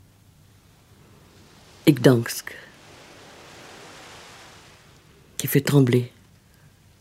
Dans la bouche de Marguerite Duras cependant, ce nom de Gdańsk est proféré dans un cahot de la gorge, comme un mot vietnamien :
Marguerite Duras (1914-1996). « Et Gdansk qui fait trembler », extrait de La jeune fille et l’enfant, adapté par Yann Andréa de L’été 80, de Marguerite Duras, lu par elle-même. 1981.
C’est ainsi qu’il faut lire L’été 80 – en écoutant en soi-même la voix de M. D. parlant de Gdańsk, ce lieu « au plus loin de nous ».